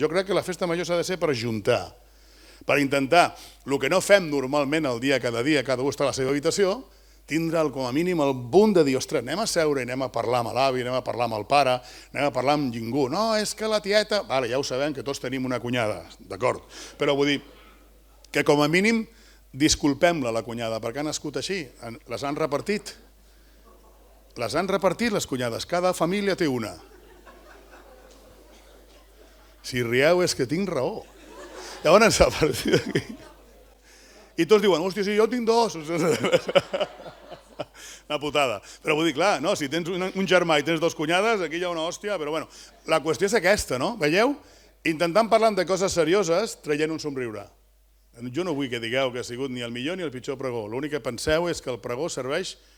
Fragment del pregó de la Festa Major d'hivern de Sant Vicenç